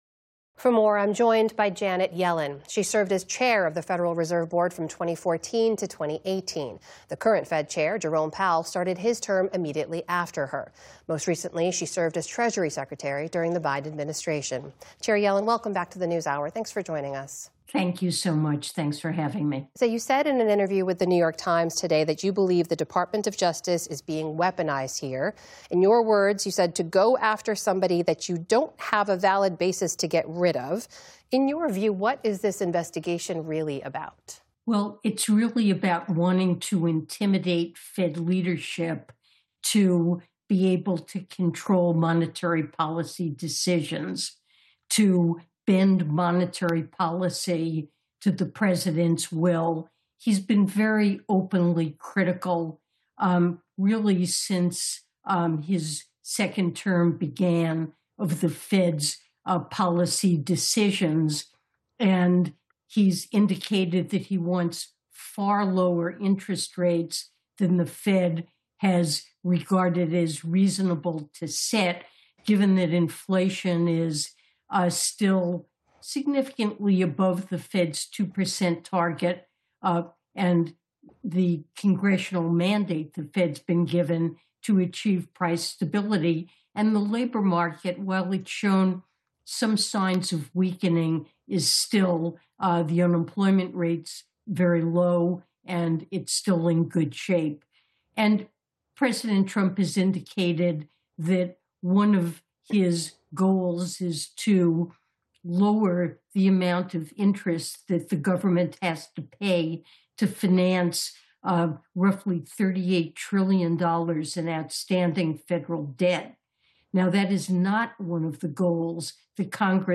As Federal Reserve Chair Jerome Powell pushes back against a criminal probe from the Trump Justice Department, Amna Nawaz discusses the developments with Janet Yellen.